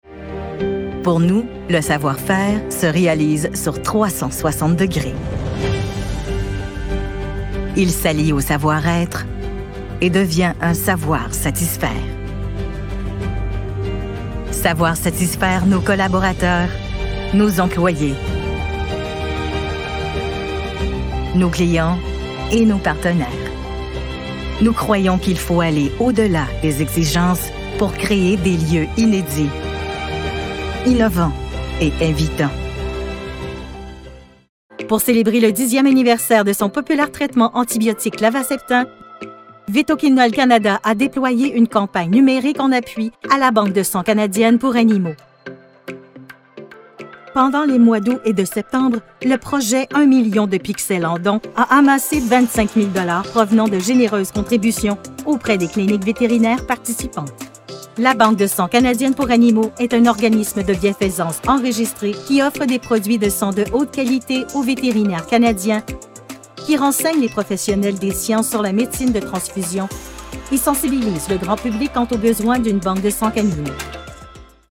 Corporate demo (FR)
French - Canadian